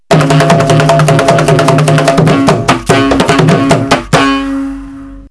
Mridang.wav